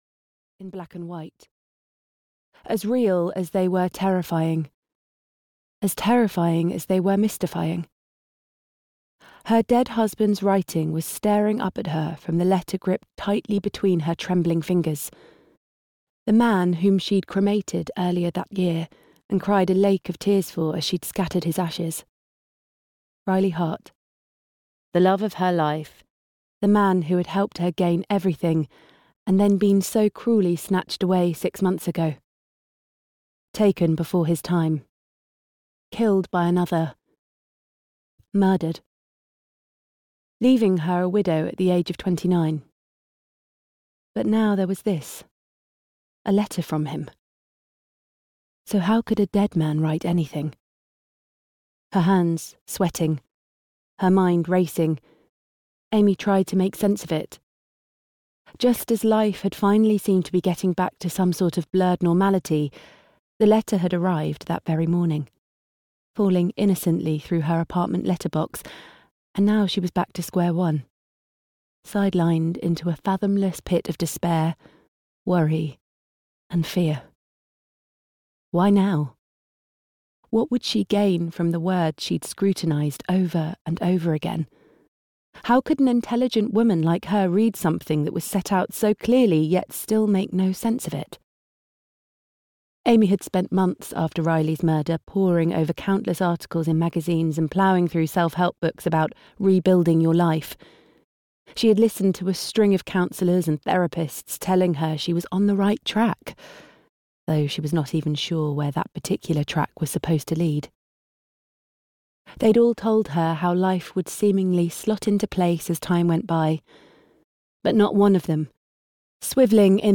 Deadly Obsession (EN) audiokniha
Ukázka z knihy